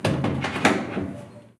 Cerrar la puerta de una lavadora 2
puerta
lavadora
Sonidos: Acciones humanas
Sonidos: Hogar